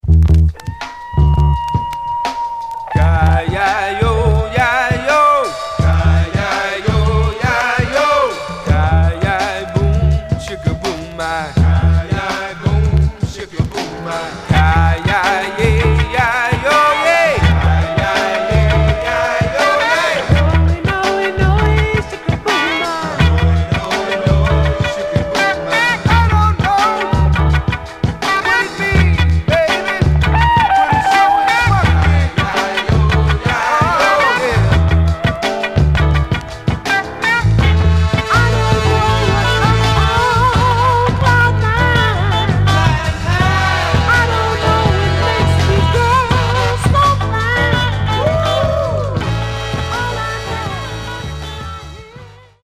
Stereo/mono Mono
Folk Condition